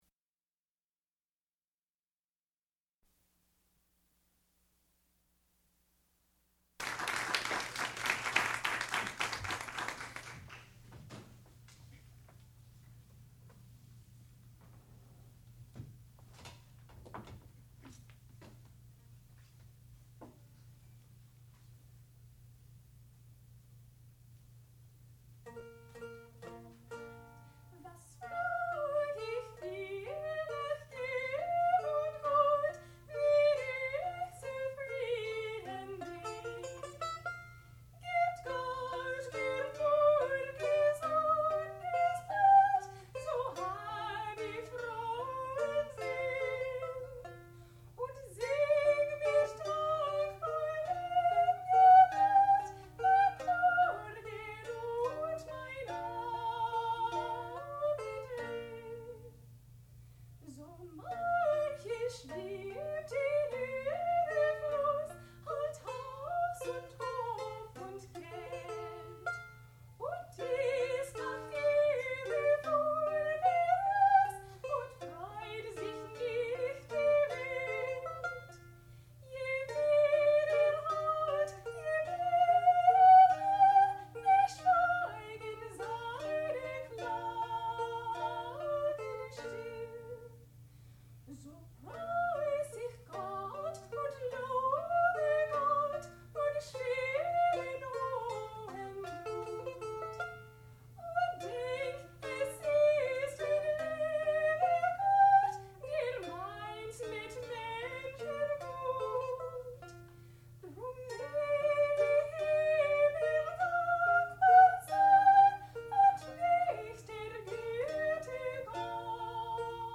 sound recording-musical
classical music
alto domra
mandolin
soprano